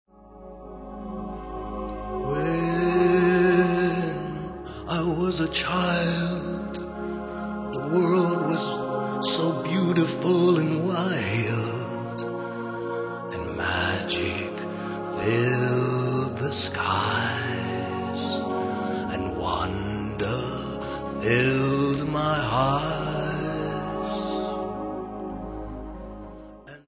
Lieder der Verzweiflung, Obsession und Erleuchtung.